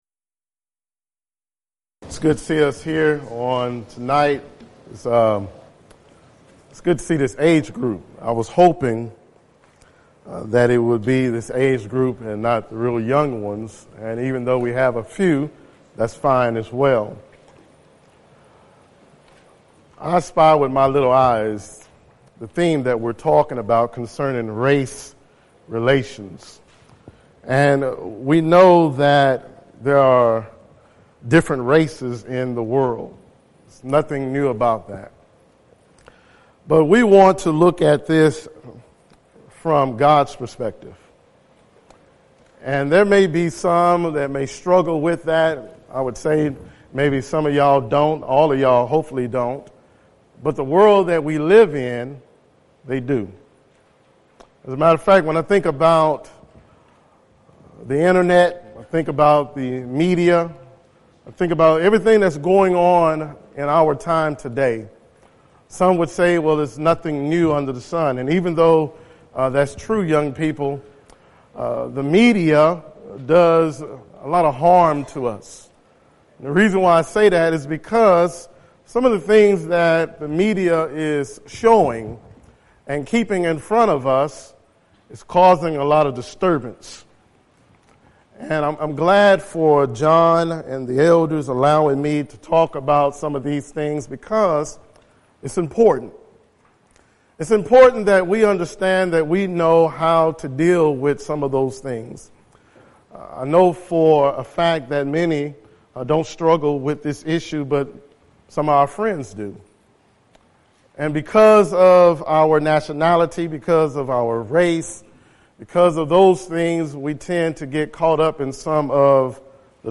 Youth Sessions